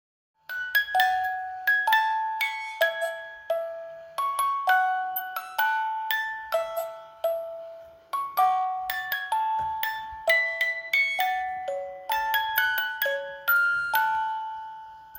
Помогите определить, что за музыка в шкатулке
Помогите, пожалуйста, определить, что за музыка играет в шкатулке.